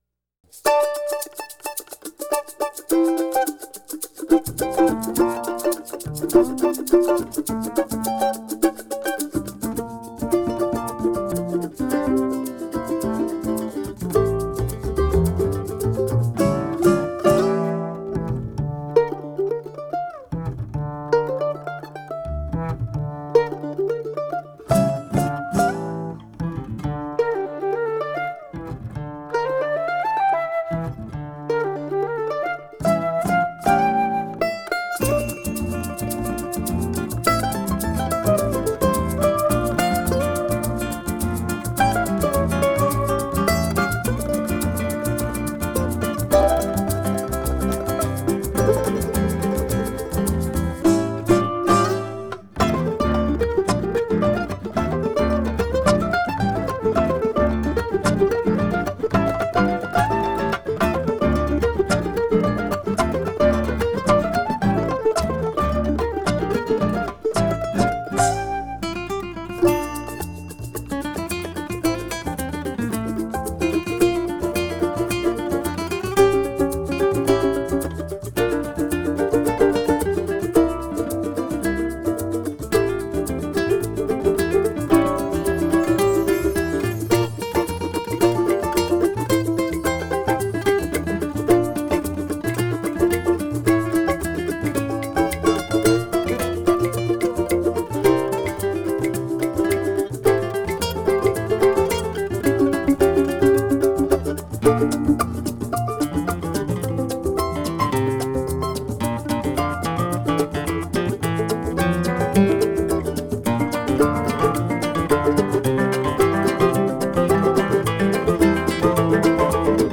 Argentine guitar master
remastered in the hi-definition format.